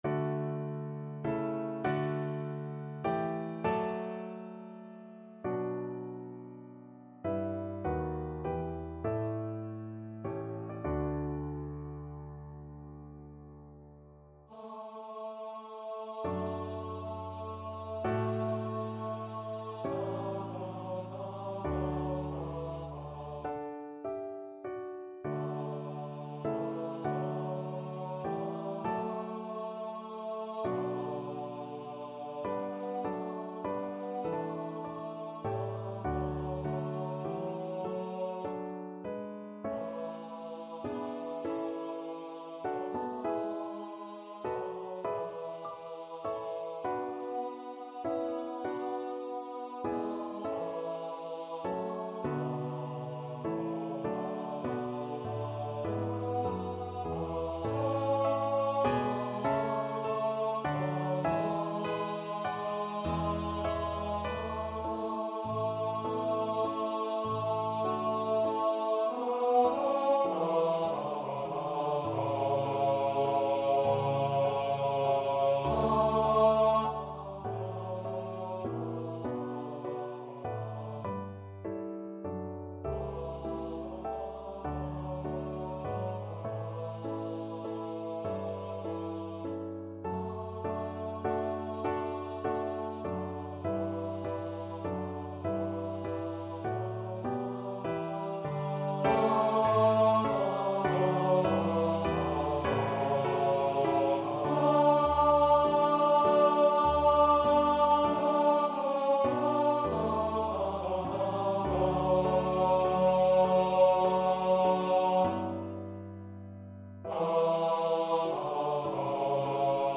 Free Sheet music for Baritone Voice
D major (Sounding Pitch) (View more D major Music for Baritone Voice )
Largo
B3-D5
3/4 (View more 3/4 Music)
Classical (View more Classical Baritone Voice Music)